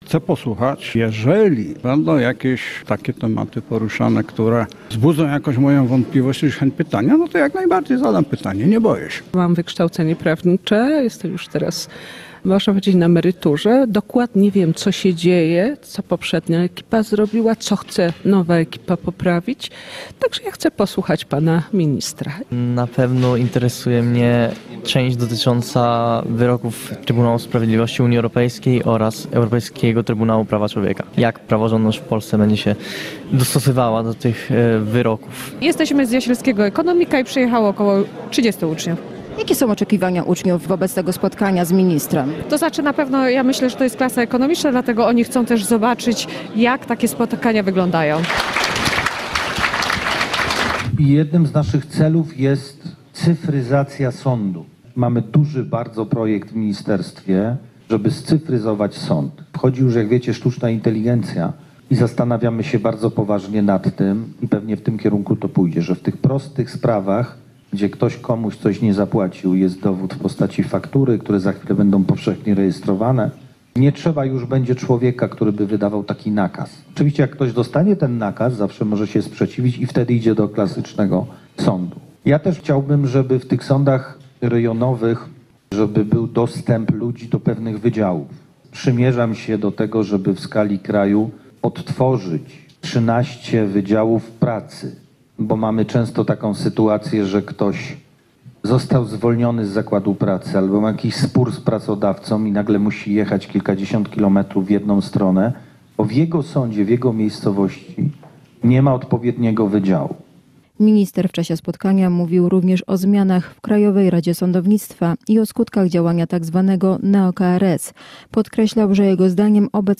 Wiadomości • Minister Sprawiedliwości, Waldemar Żurek spotkał się z mieszkańcami Jasła w Generatorze Nauki. Zapowiedział cyfryzację sądów z wykorzystaniem sztucznej inteligencji, odtworzenie 13 wydziałów pracy oraz zmiany w Krajowej Radzie Sądownictwa. Mówił też o ponad tysiącu wakatów sędziowskich i skutkach działania tzw. neo-KRS.